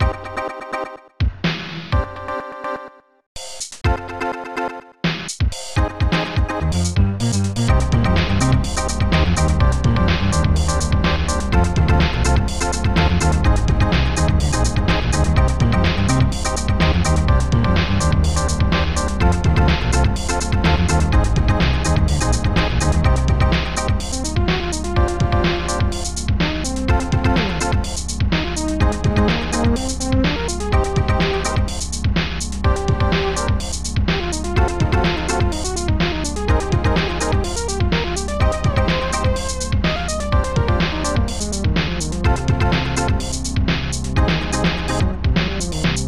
Protracker Module
Worgan1 Worgan2 Worgan3 Worgan4 Worgan5 Nightmar Bassd4sa Hallsnar 34hihat1 Enighats Ridecyms Dreampad St8sam